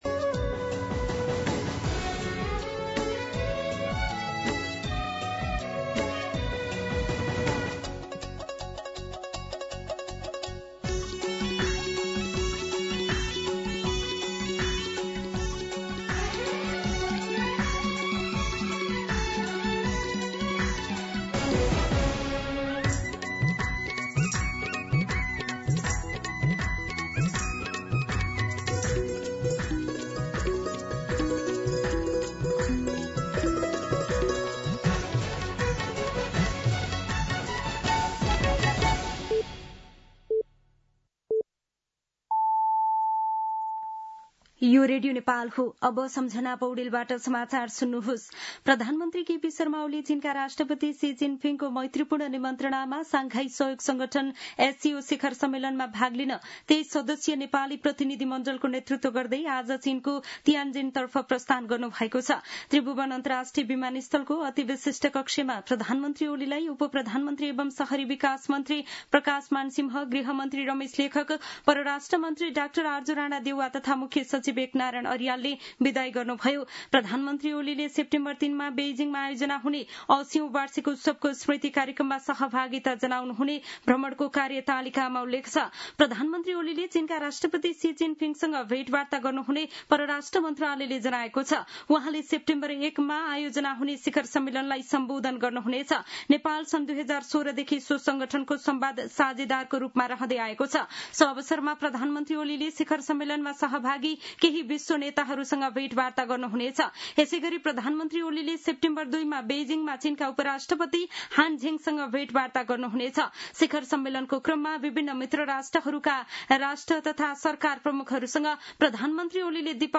दिउँसो १ बजेको नेपाली समाचार : १४ भदौ , २०८२